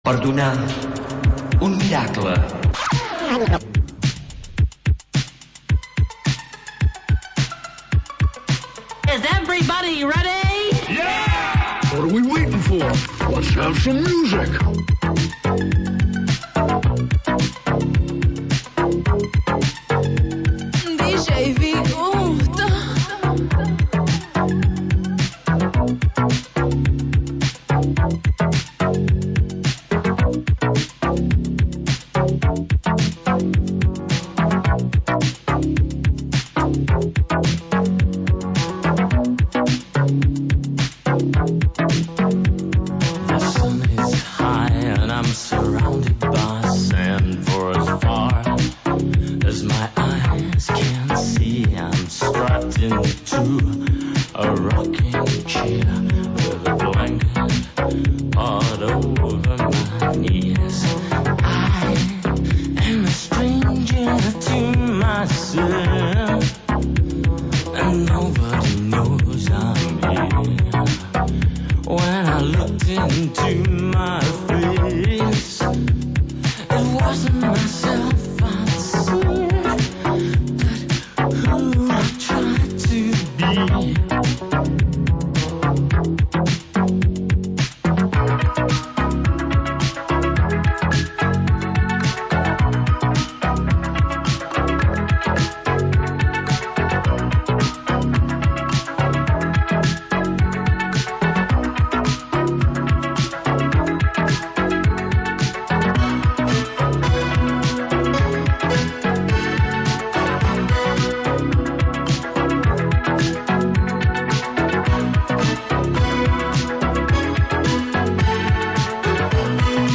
Selecció musical independent